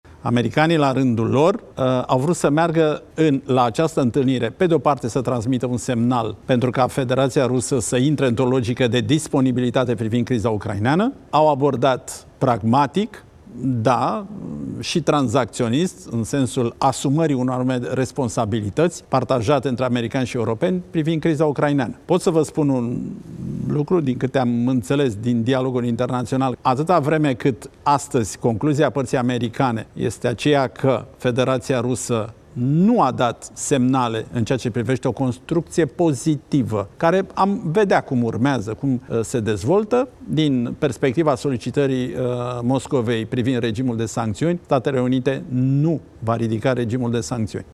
Consilierul prezidențial pentru apărare și siguranță națională, Cristian Diaconesu susține într-un interviu acordat postului Antena 3 că partea americană a „refuzat, în acest moment, dar nu avem garanții”.